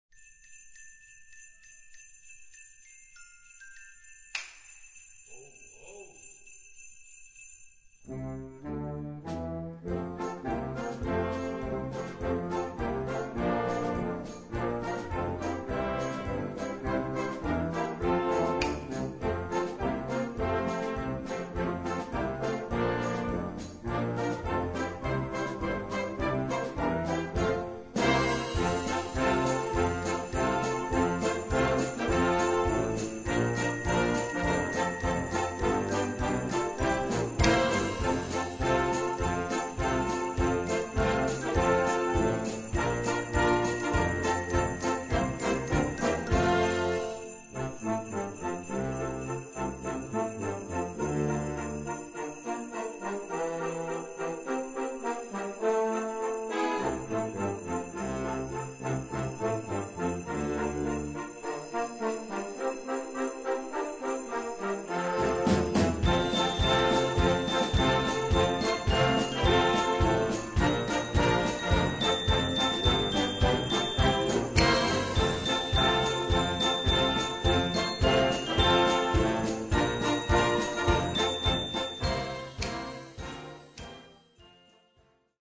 Inkl. Kinderchor ad lib.
Noten für flexibles Ensemble, 4-stimmig + Percussion.